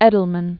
(ĕdl-mən), Gerald Maurice 1929-2014.